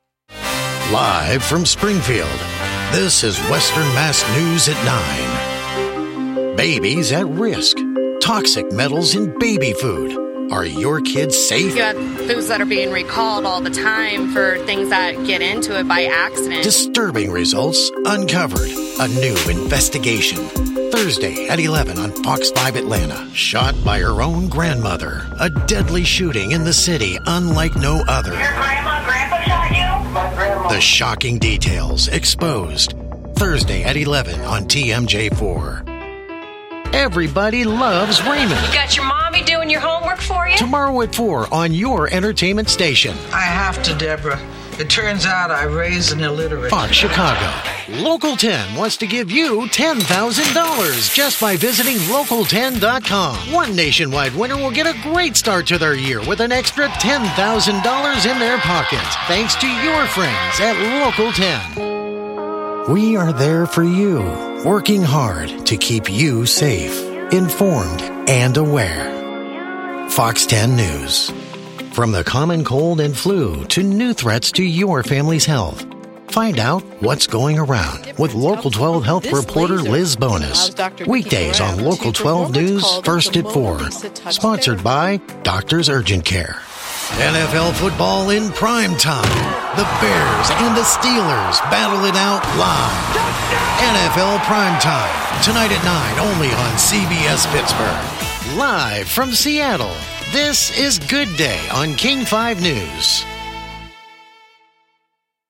Male
Adult (30-50), Older Sound (50+)
Radio / TV Imaging
Tv Affiliate Sample